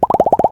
fbrawl_bubble_beam.ogg